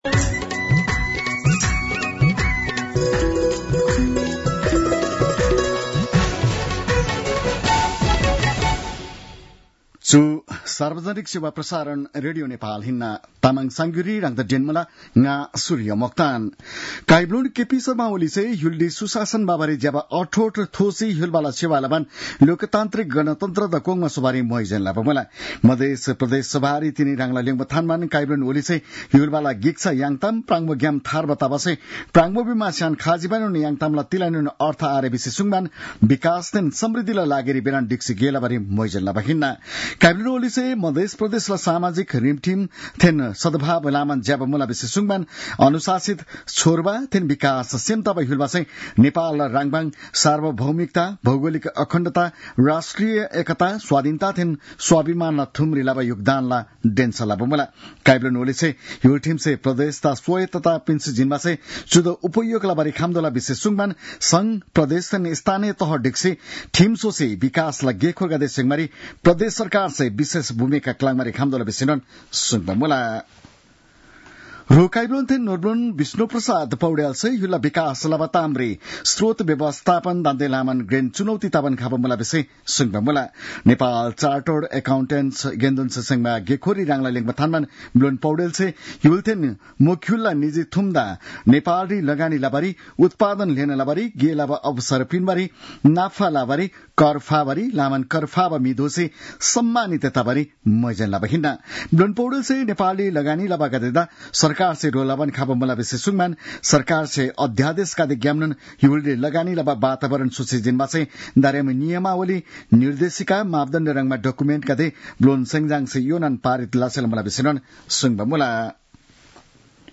तामाङ भाषाको समाचार : २६ चैत , २०८१